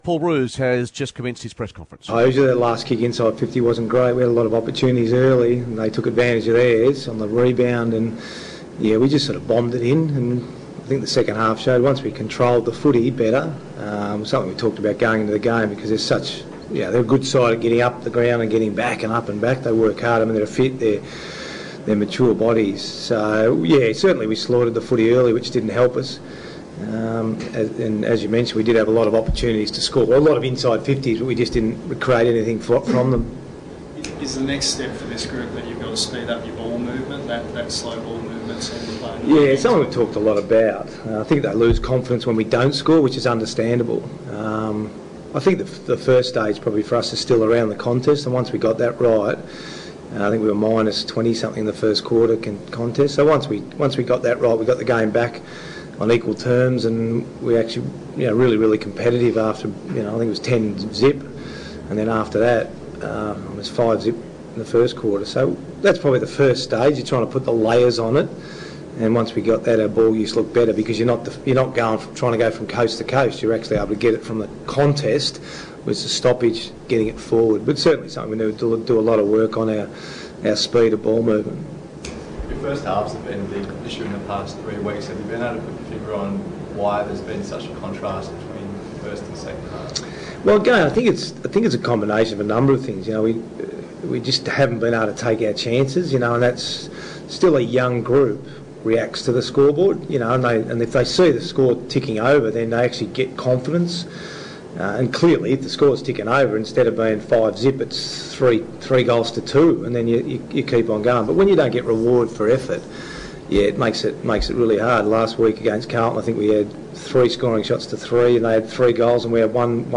Paul Roos Post Game Press Conference